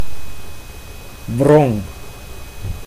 Ääntäminen
IPA: /vrɔŋ/